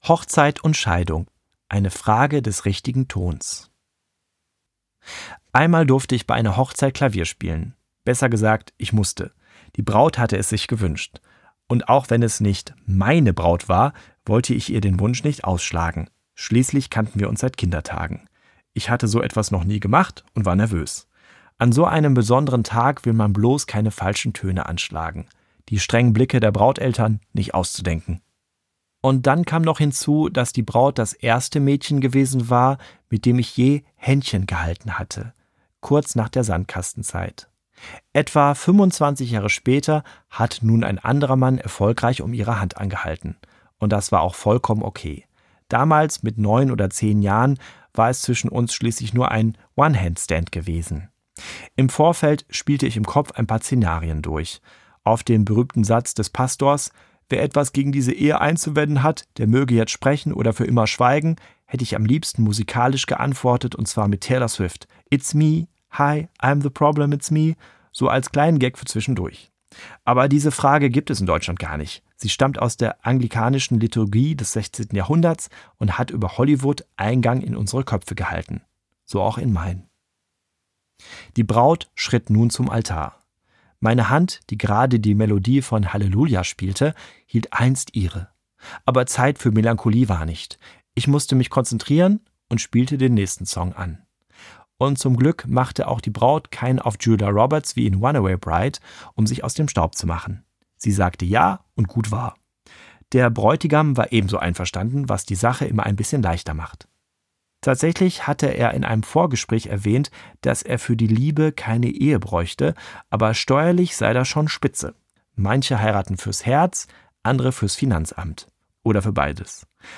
Das Hörbuch, das auf wissenschaftlichen Erkenntnissen beruht, bietet für jede Lebensphase den richtigen Soundtrack.
Gekürzt Autorisierte, d.h. von Autor:innen und / oder Verlagen freigegebene, bearbeitete Fassung.